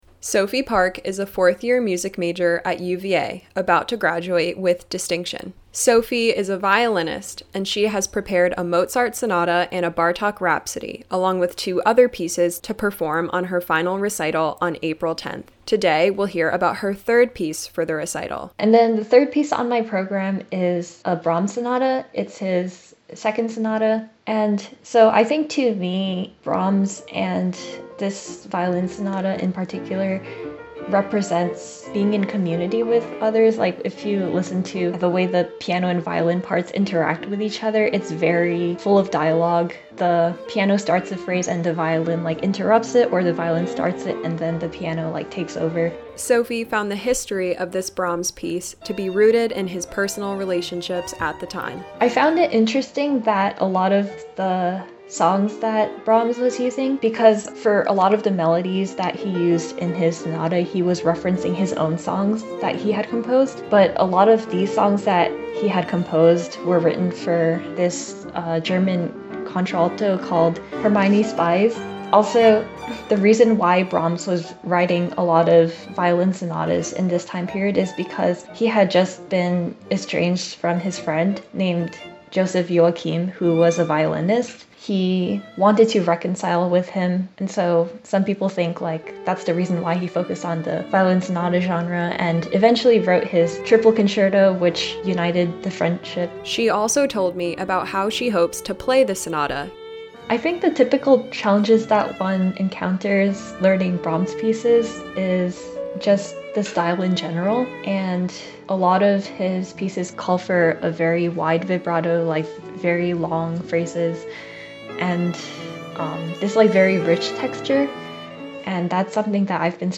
Classical Interviews
These interviews air as part of WTJU’s Classical Sunrise, weekday mornings from 7-9am.